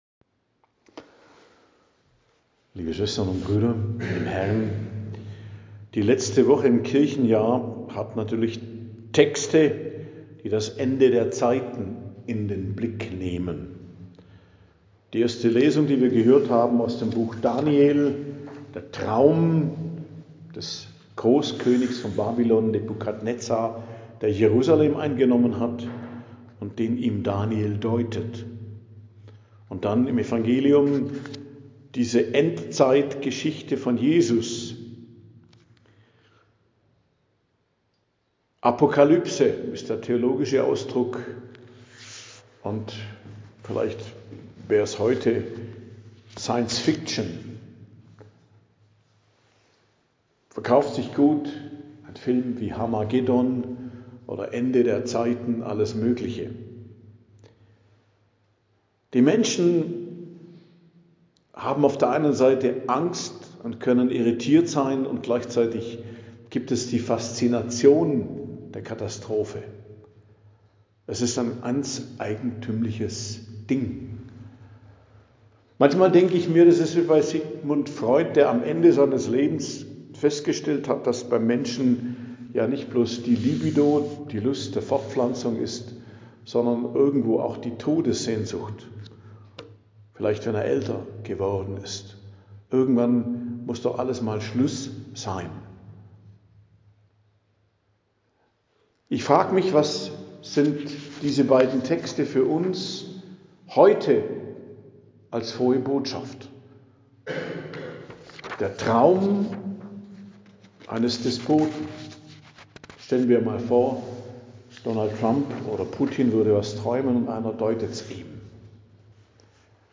Predigt am Dienstag der 34. Woche i.J., 25.11.2025